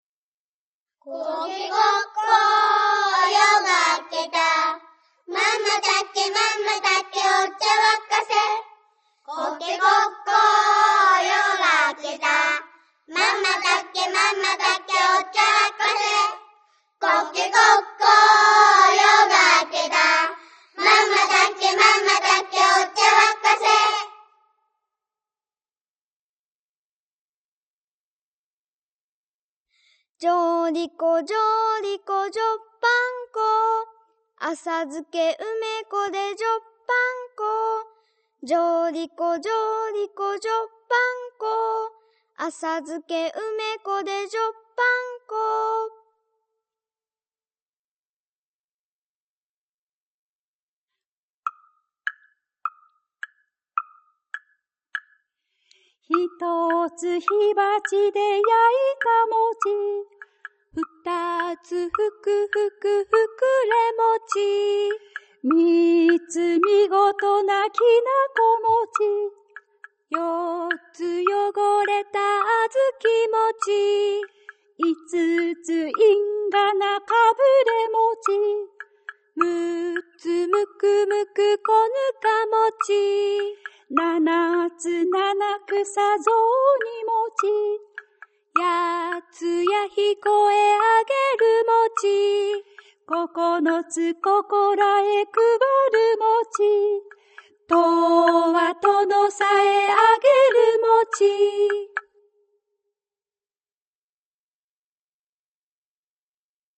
季節や行事の歌が盛り沢山で、日常保育に生活発表会にと一年中大活躍です。子どもの歌声も沢山収録し、そのまま流しておくだけで一緒になって口ずさめます。
2010年の酷暑の夏、〈空色の家〉の子どもたちといろいろな場でわらべうたを実践している大人たち総動員で録音に取り組んだ。